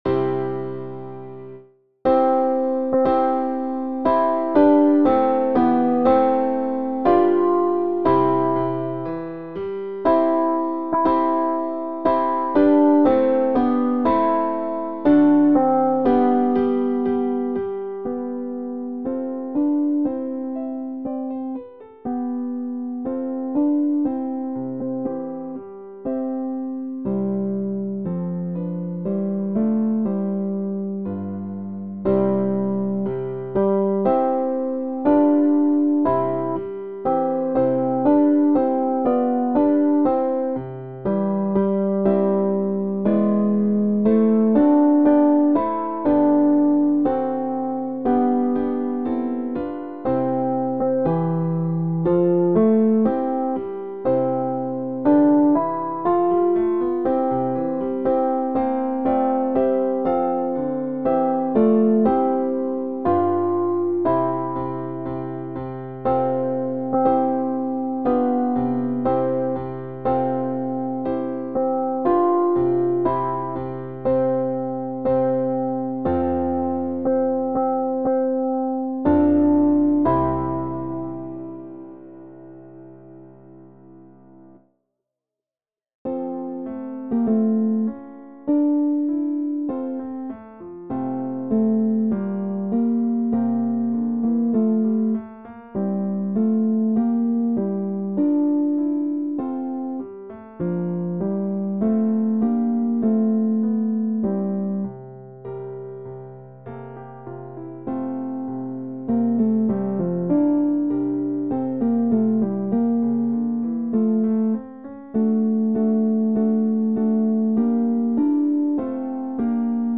2_Gloria - Ténor - Chorale Concordia 1850 Saverne
2_Gloria-Ténor.mp3